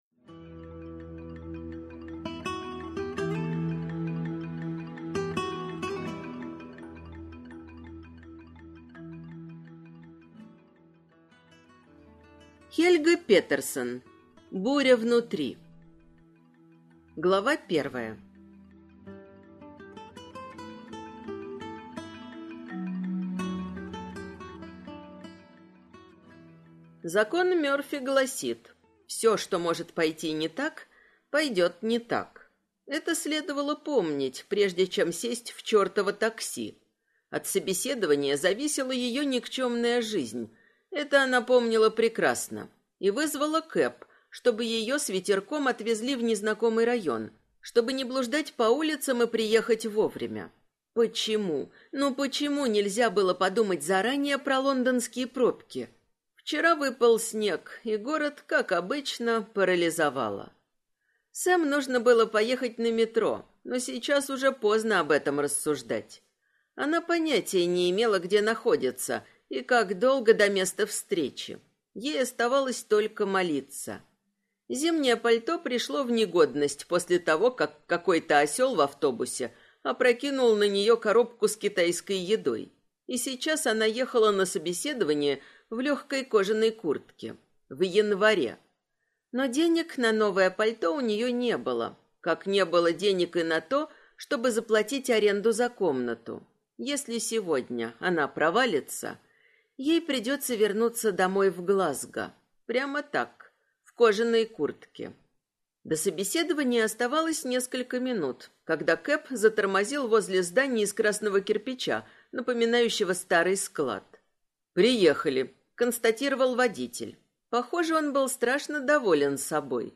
Аудиокнига Буря внутри | Библиотека аудиокниг